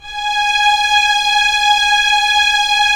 Index of /90_sSampleCDs/Roland - LA Composer vol. 4/STR_TIDE 01/STR_'Violins _